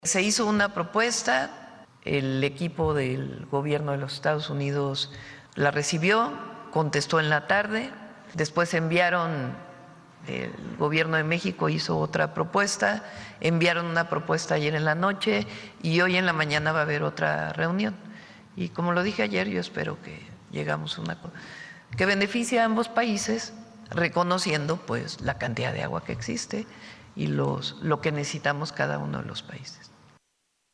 Habla la mandataria federal.